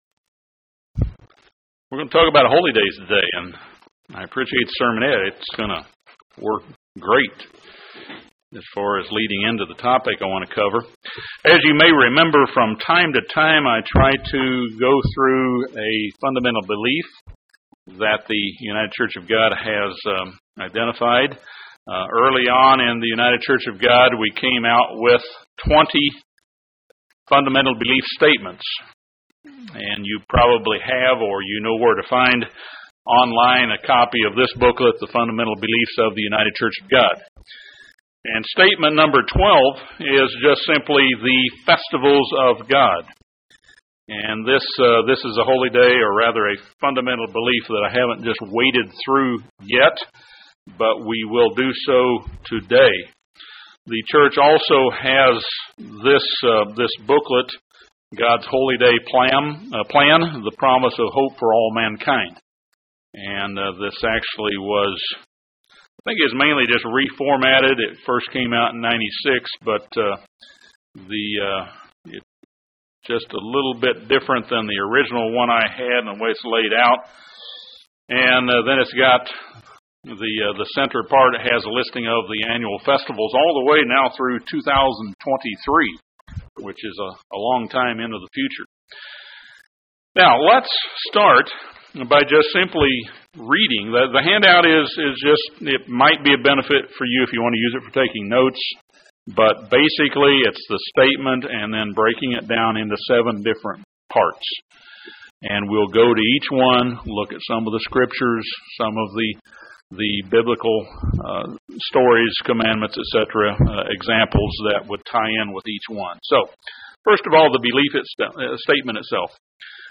Our worship of God should include observance of the appointed times that He commands us to appear before Him in worship. In this sermon, we see how God created appointed times for His feasts at the very foundation of the world; and how we should follow the example of the New Testament church in keeping those appointed festivals of God.